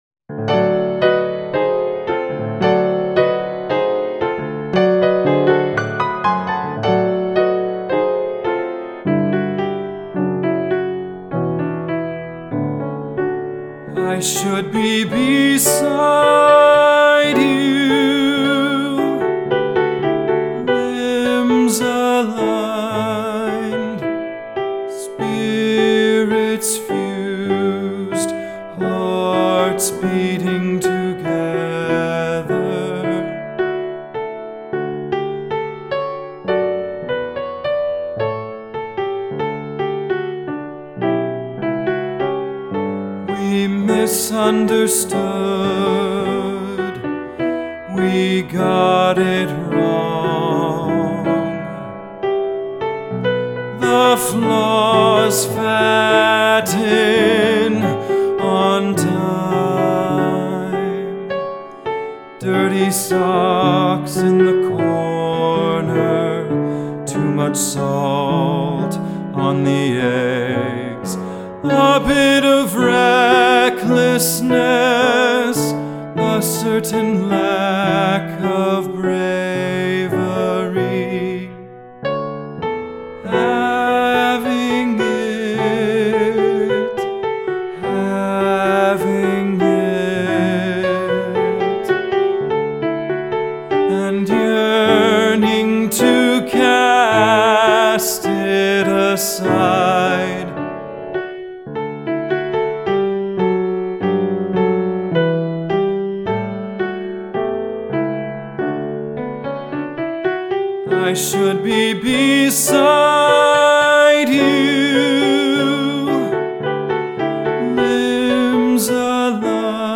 cast recording